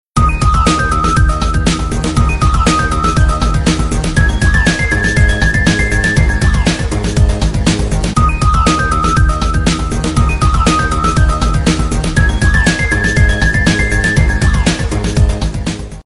• Качество: 128, Stereo
громкие
без слов
Electronica
динамичные
электронные
Динамичный рингтон на входящий звонок